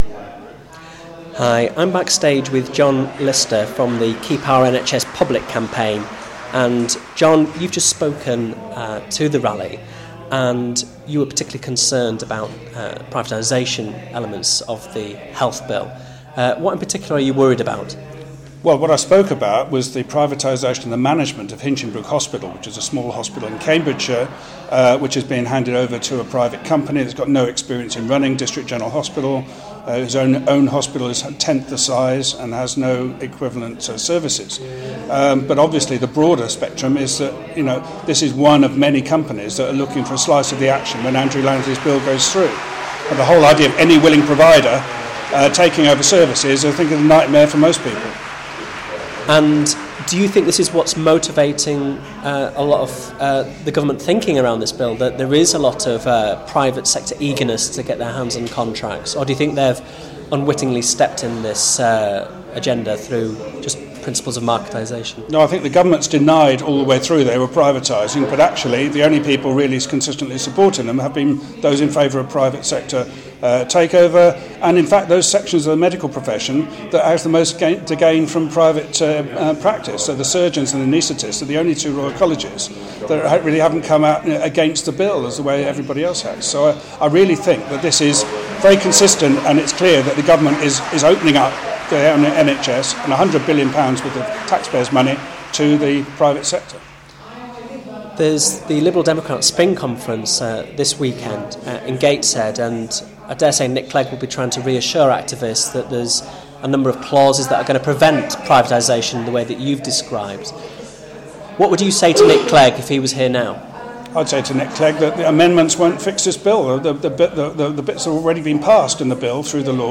at the Save Our NHS rally